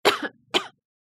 Потенциально больная женщина кашляет в маску